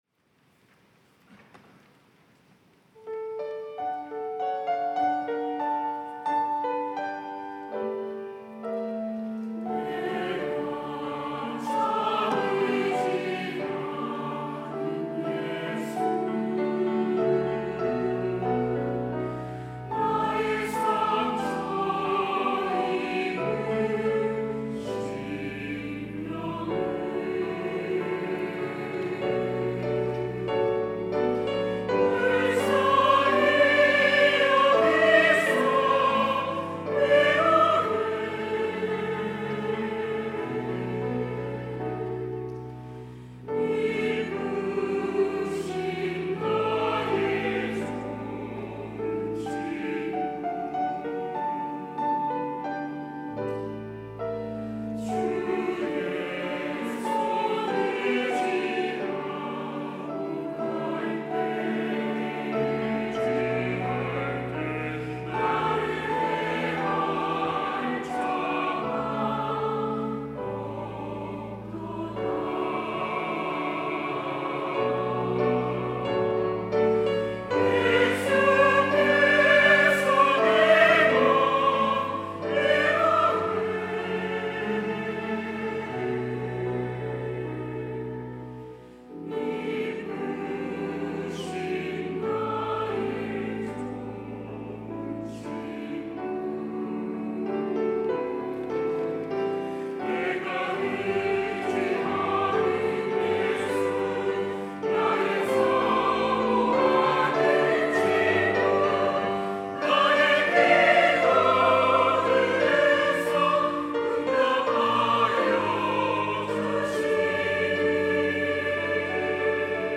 호산나(주일3부) - 내가 참 의지하는 예수
찬양대